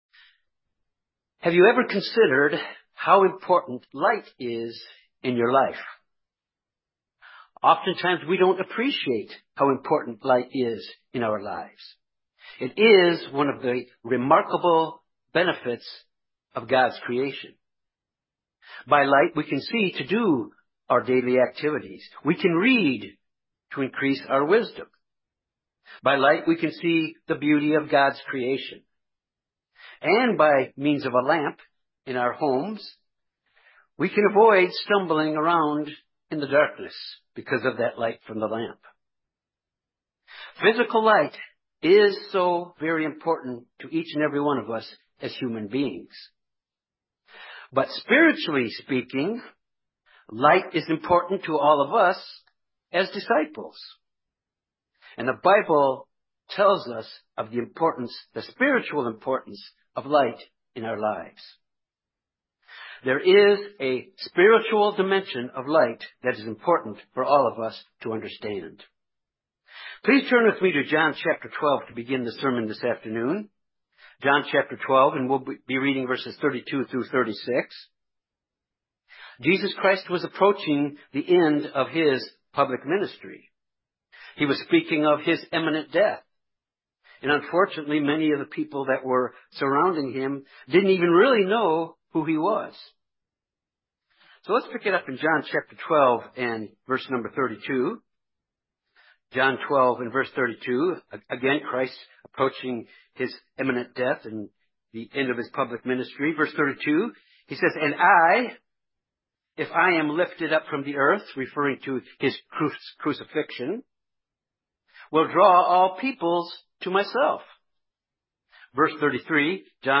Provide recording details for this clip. Given in Jonesboro, AR Little Rock, AR